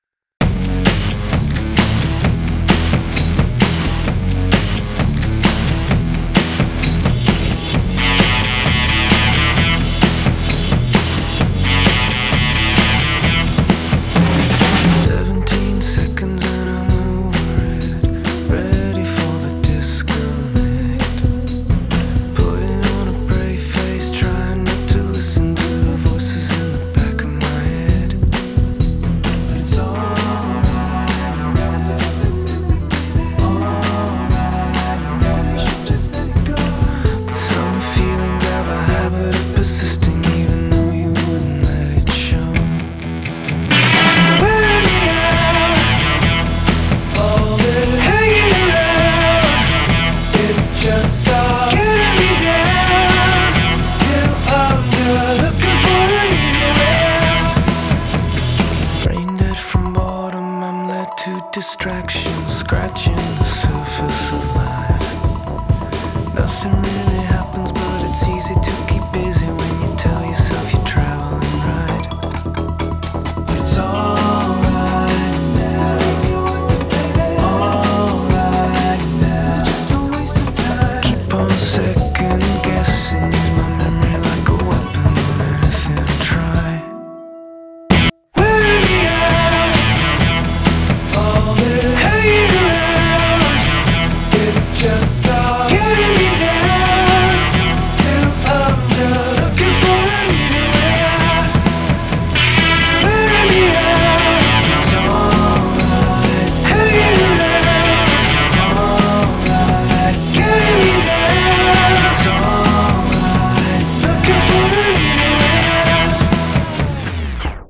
Genre: Indie Rock / Electronic / Alternative